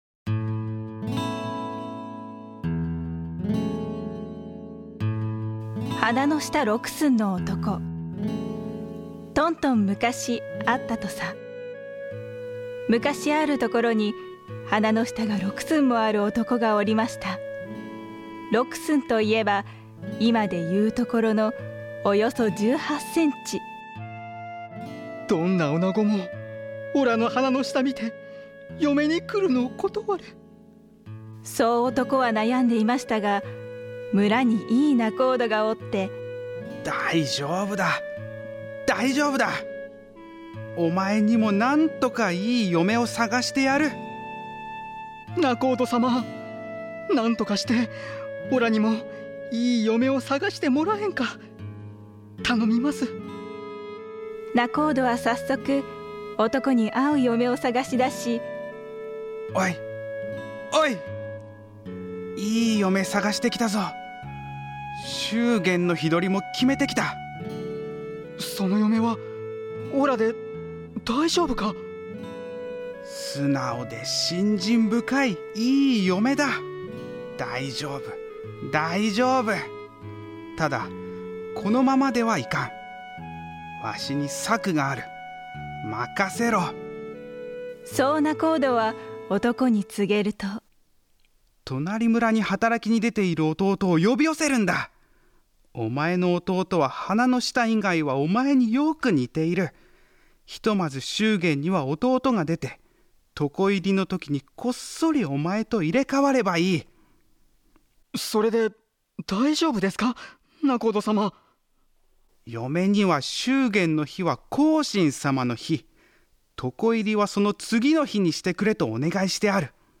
青年部ならだからこそ、演じることのできた昔話をお楽しみください。 出演：劇団ひまわり新潟エクステンションスタジオ